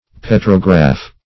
petrograph \pet"ro*graph`\ (p[e^]t"r[-o]*gr[a^]f`), n.